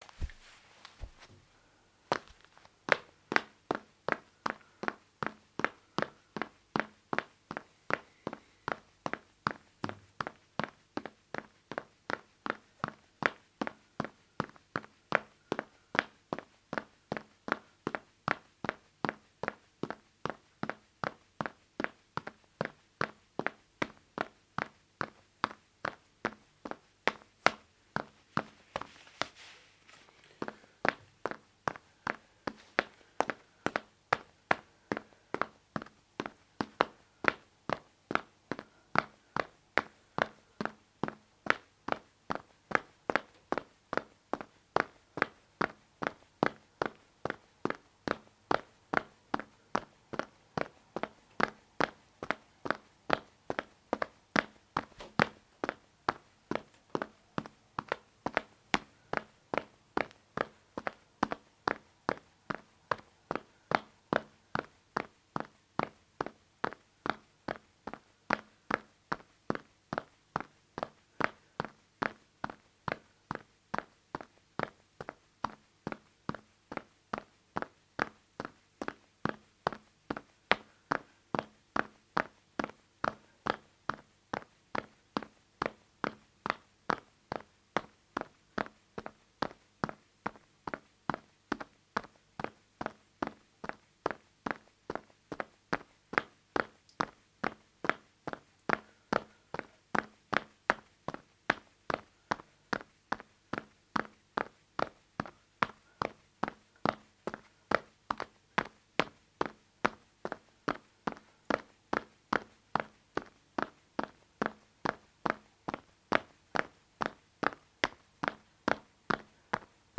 混凝土台阶001
描述：走一套具体步骤的人。